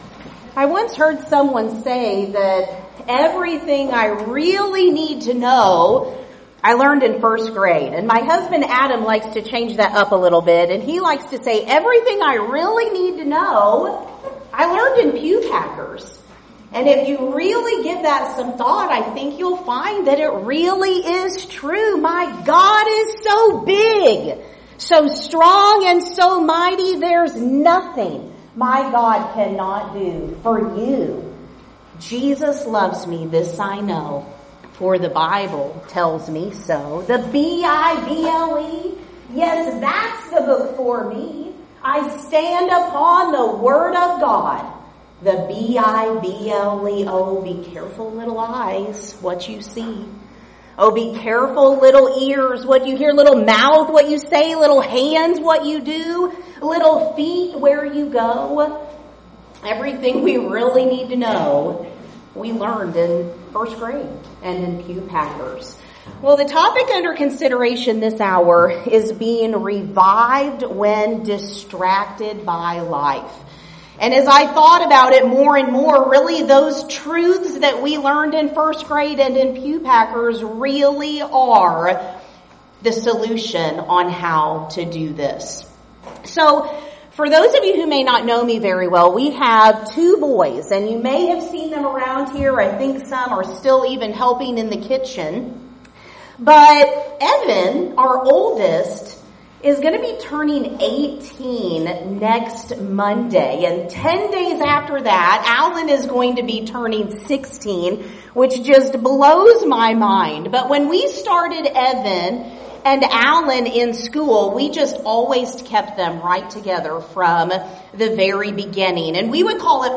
Event: 7th Annual Women of Valor Ladies Retreat
Filed Under (Topics): Ladies Sessions